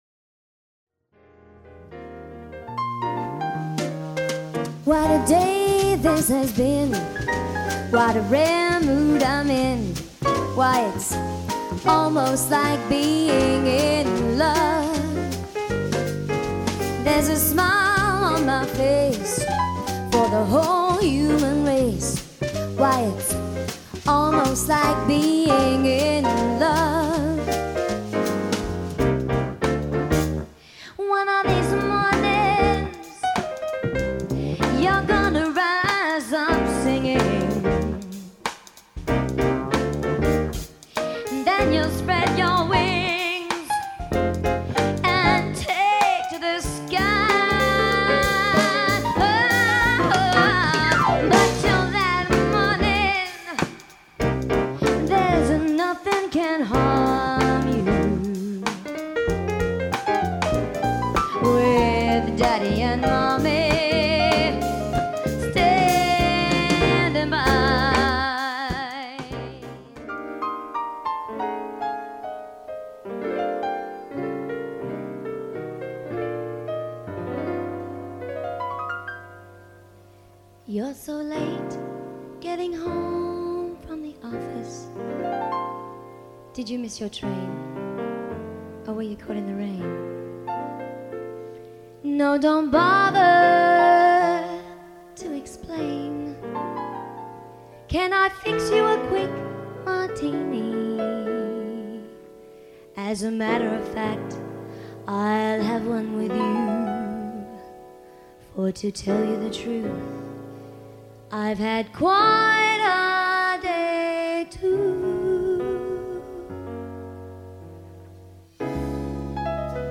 …for Jazz Clubs, Corporate functions and Private Events